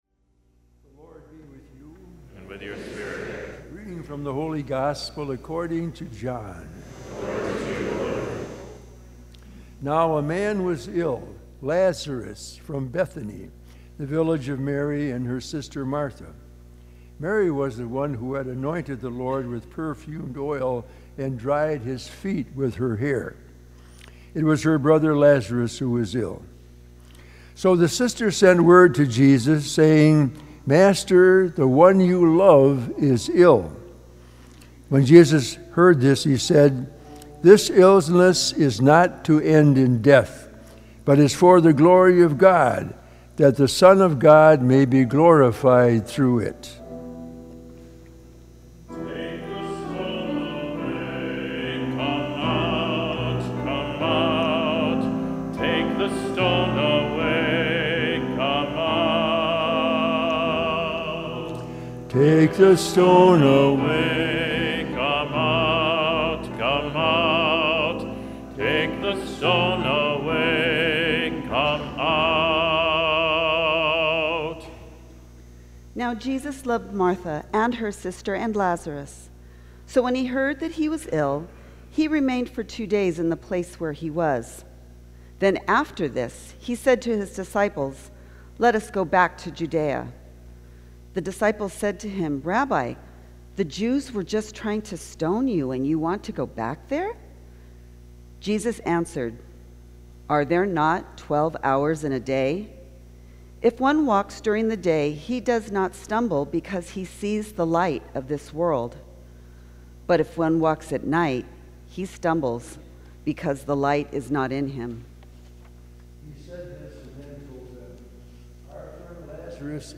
Gospel and Homily Podcasts
Holy Family Church Twenty-fifth Sunday in Ordinary Time, September 23, 2018, 11:15 Mass Play Episode Pause Episode Mute/Unmute Episode Rewind 10 Seconds 1x Fast Forward 30 seconds 00:00 / 24:13 Subscribe Share